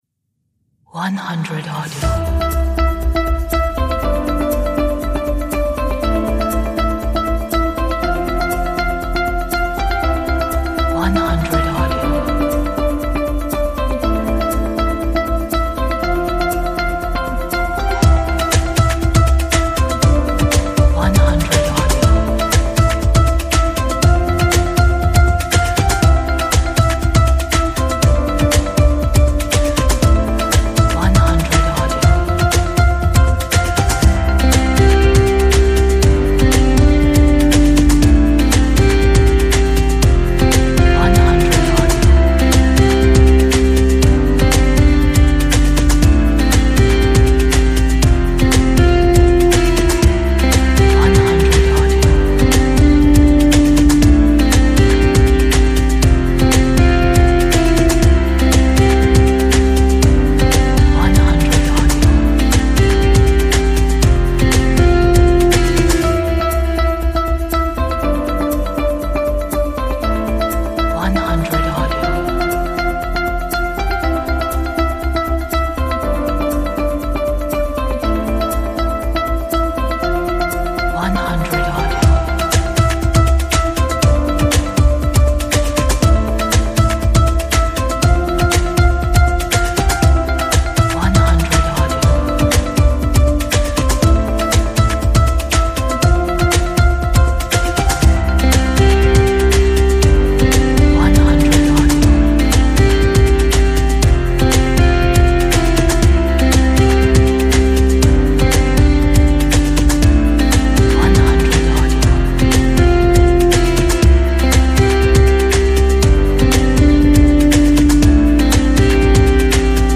a bright pop upbeat uplifting track
这是一首明亮 乐观和振奋人心的流行音乐。